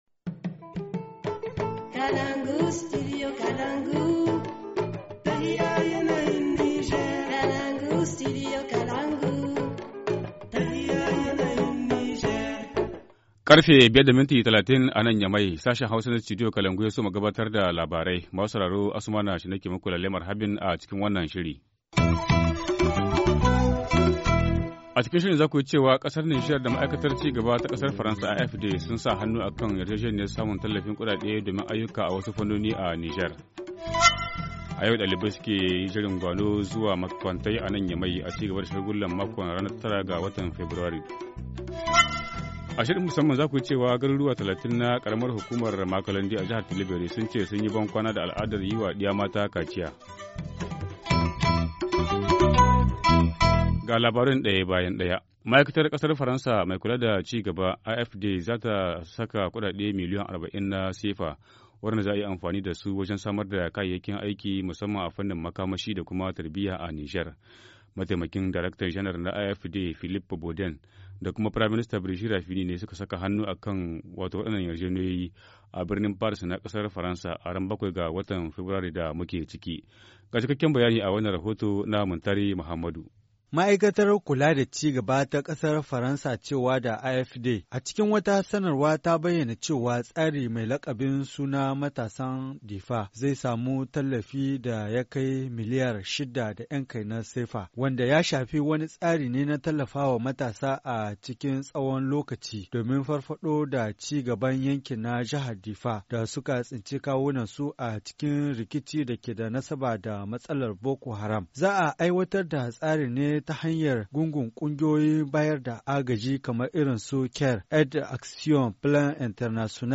Journal du 16 février 2017 - Studio Kalangou - Au rythme du Niger